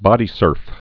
(bŏdē-sûrf)